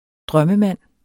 Udtale [ -ˌmanˀ ]